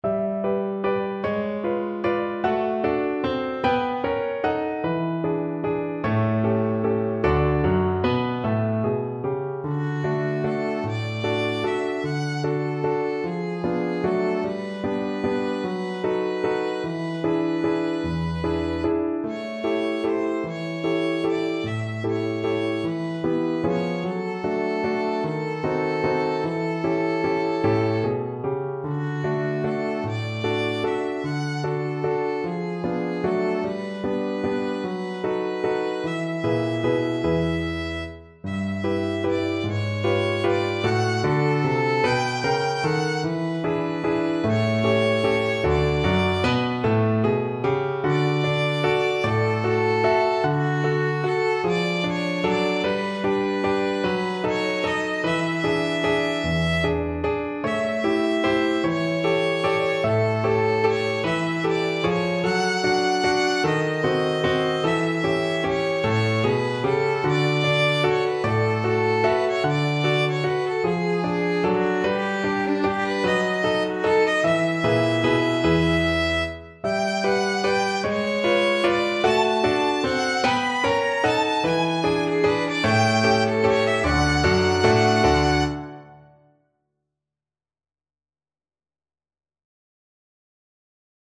Violin
3/4 (View more 3/4 Music)
D5-B6
Moderato . = c. 50
D major (Sounding Pitch) (View more D major Music for Violin )